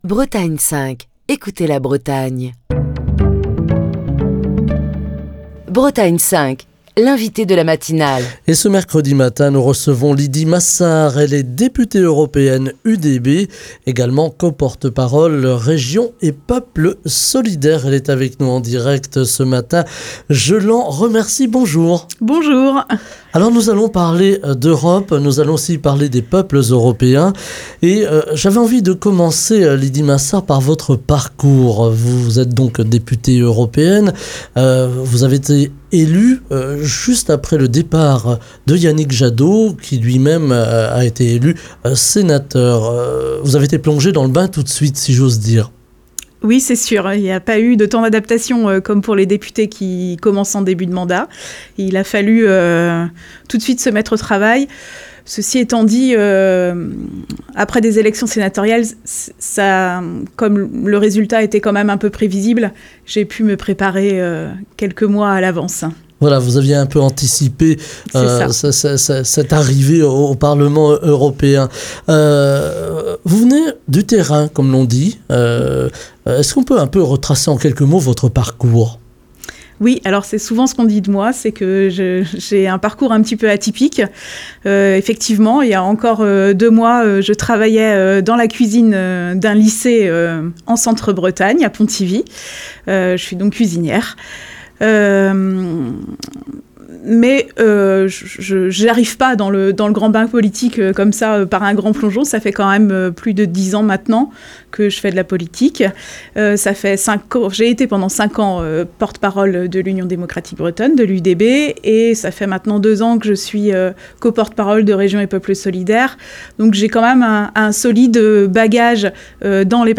Pour la première fois, l'Union Démocratique Bretonne entre au Parlement européen, ce qui constitue un espoir pour les formations régionalistes de se renforcer et de peser sur les décisions européennes. Ce mercredi, Lydie Massard, députée européenne UDB, co-porte-parole de Régions et Peuples Solidaires, invitée de Bretagne 5 Matin, revient sur les priorités de cette courte mandature de 7 mois avant les élections européennes, parmi lesquelles le dossier agricole, avec la réforme de la PAC, les nouveaux OGM, ou encore les langues régionales.